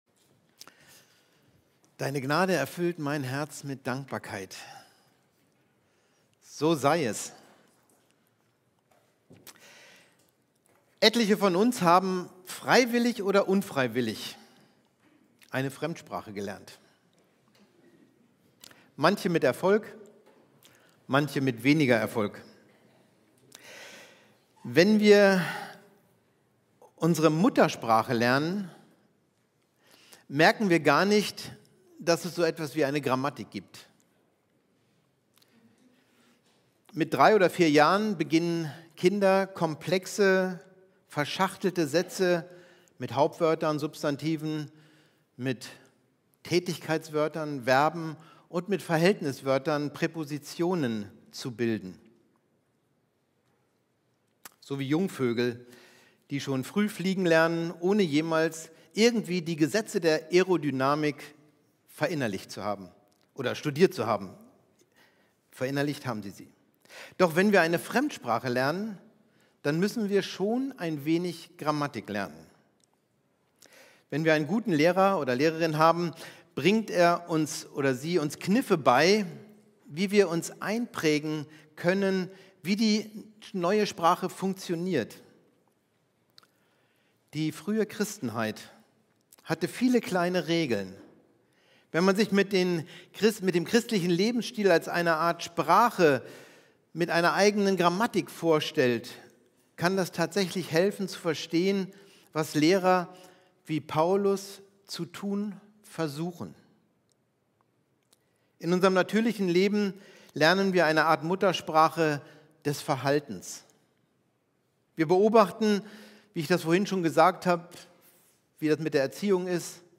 Predigt mp3